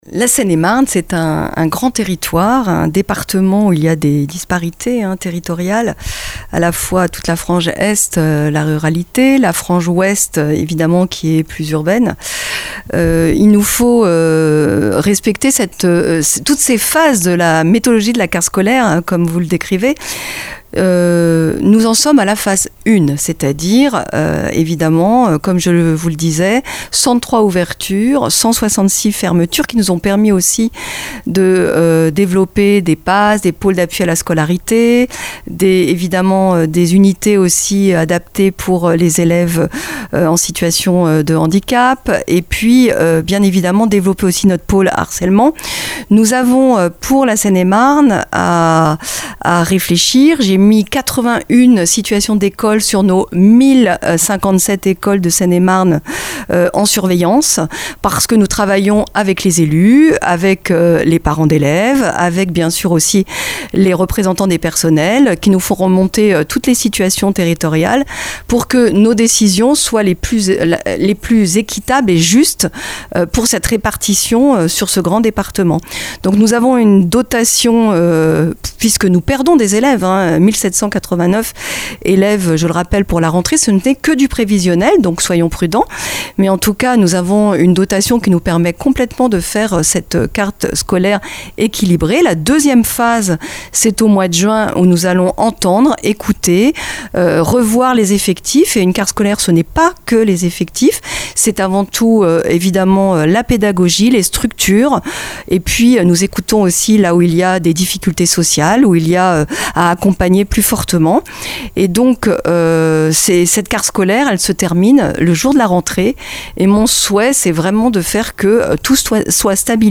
CARTE SCOLAIRE - La Directrice Académique justifie les décisions d'ouvertures et de fermetures de classes en Seine-et-Marne - Radio Oxygène
Alors que les première décisions pour la carte scolaire de septembre 2026 viennent d'être dévoilés, nous avons rencontré Aline Vo-Quang, directrice académique en Seine-et-Marne, pour évoquer les décisions et parler de l'avenir des écoles, collèges et lycées dans notre département.